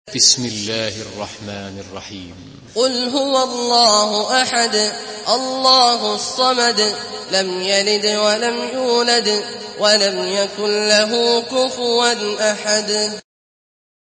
Surah Ihlas MP3 in the Voice of Abdullah Al-Juhani in Hafs Narration
Murattal Hafs An Asim